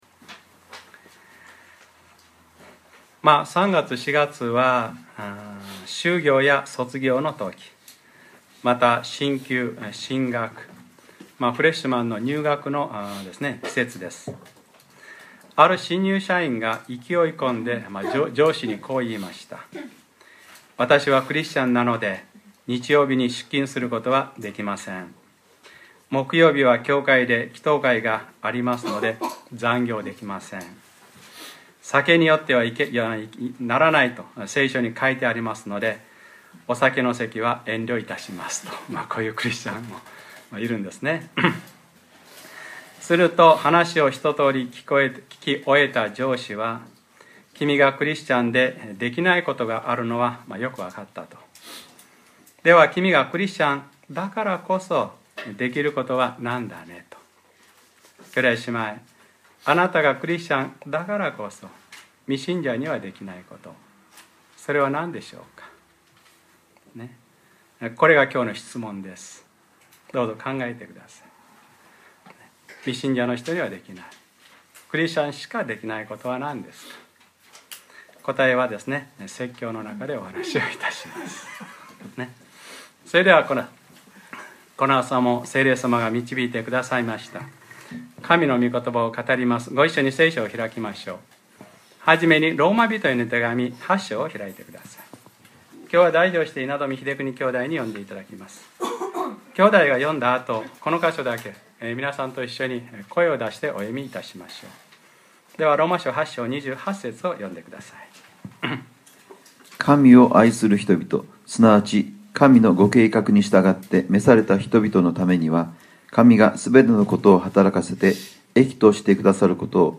2014年 3月 9日（日）礼拝説教『全てのことを働かせて益としてくださる』